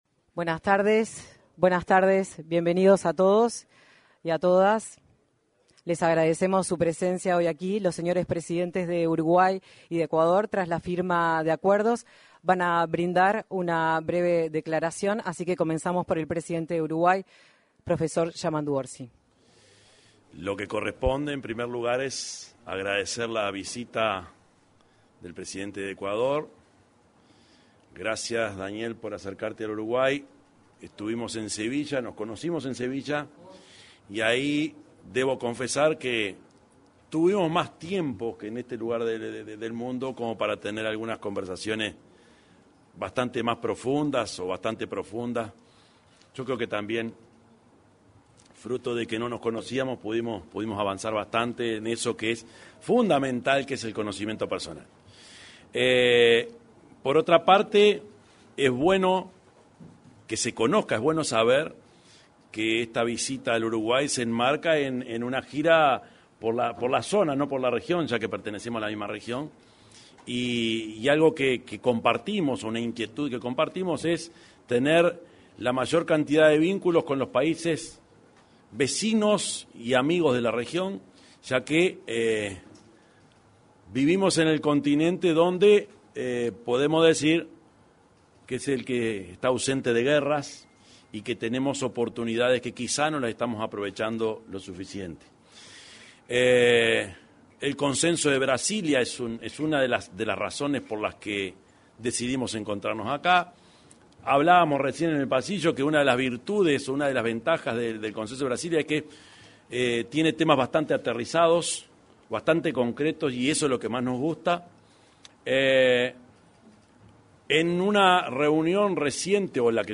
Declaración conjunta de los presidentes de Ecuador y Uruguay 19/08/2025 Compartir Facebook X Copiar enlace WhatsApp LinkedIn Los presidentes de Ecuador, Daniel Noboa, y Uruguay, Yamandú Orsi, efectuaron declaraciones en la sala de prensa de la Torre Ejecutiva, una vez finalizada la reunión entre los mandatarios.